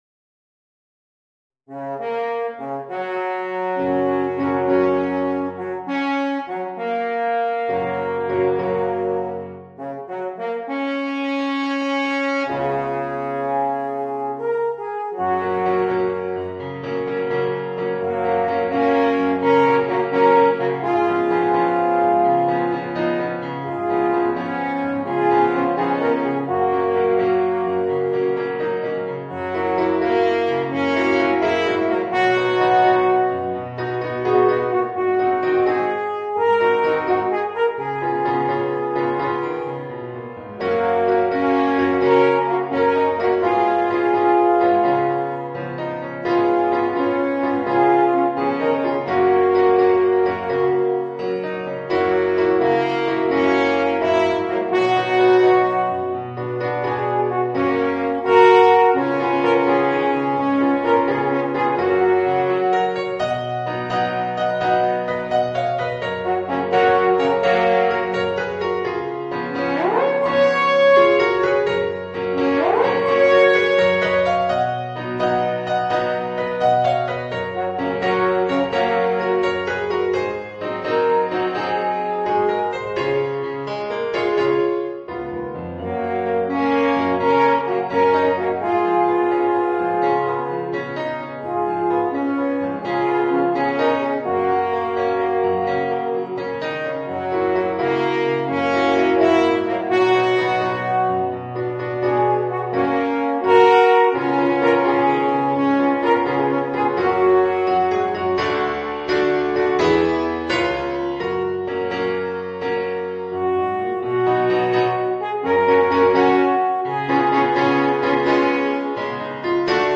2 Alphorns & Organ (2 Alphorns in Gb / Sol bémol)